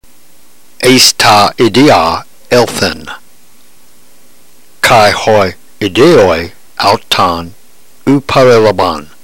Notice that the words not accented are glided into the following word without hesitation.